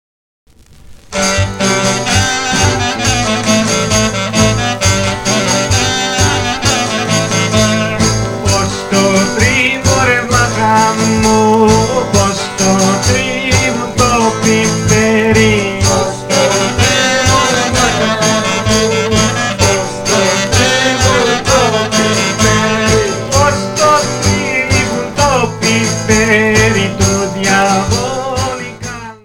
Syrtos Sta Tria
klarino, vocal
lavouto
guitar